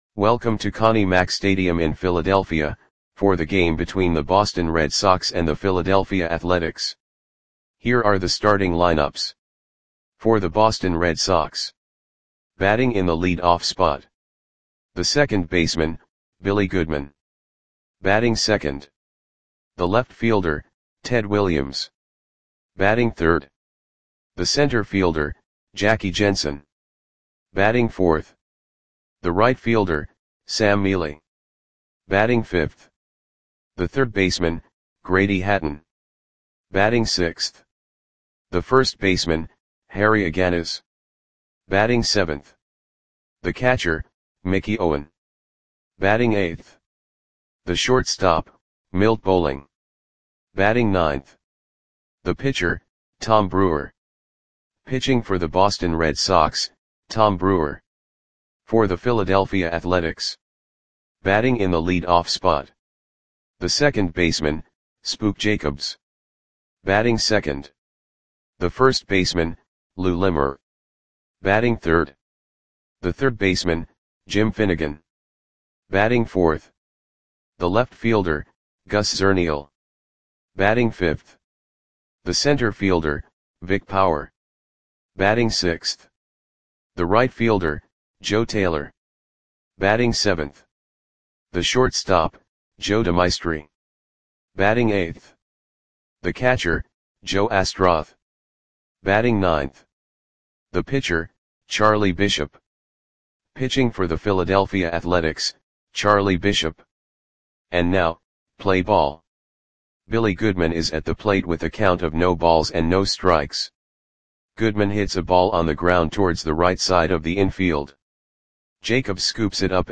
Click the button below to listen to the audio play-by-play.